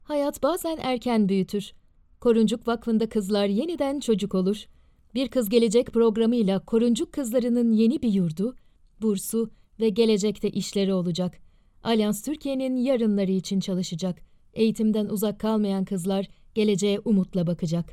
My voice style is natural and conversational, with a neutral accent. My voice is very warm, youthful, expressive and extremely professional, understanding each briefing in a unique way....